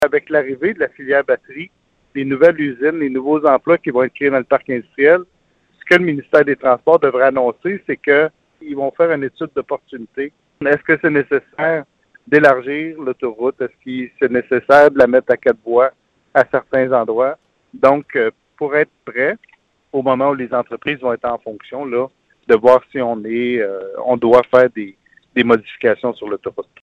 En entrevue sur nos ondes, le député Donald Martel y est allé de son hypothèse. Il pense que pendant cette période, l’impatience des automobilistes a pu être exacerbée par l’ajout temporaire d’un quatrième feu de circulation, à l’intersection de l’avenue des Jasmins.